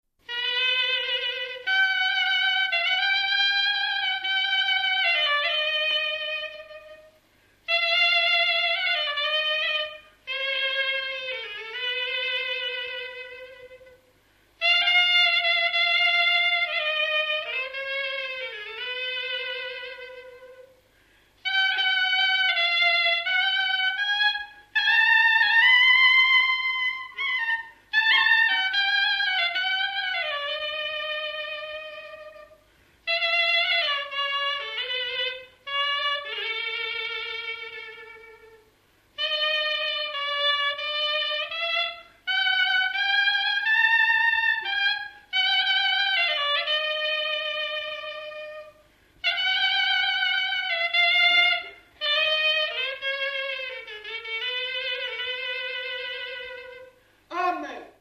Dallampélda: Hangszeres felvétel
Hangszeres felvétel Moldva és Bukovina - Bukovina - Andrásfalva Előadó
klarinét